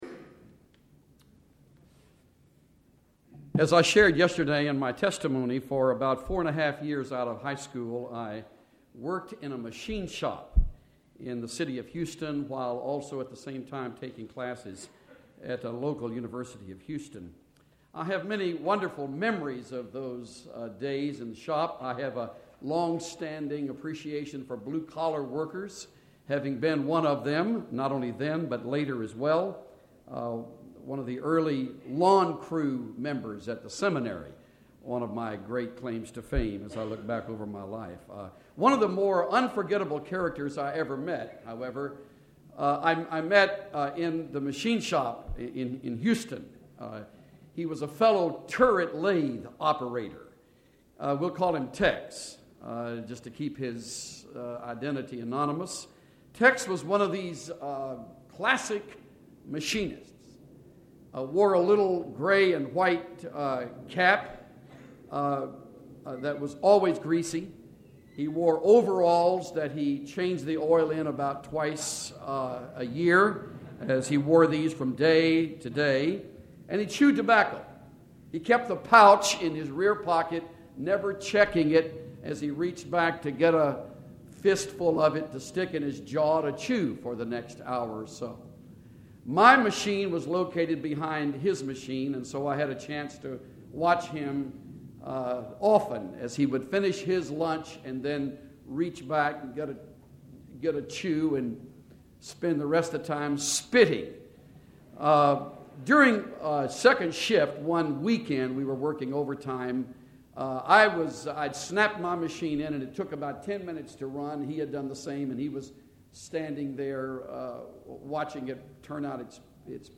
Message to Graduates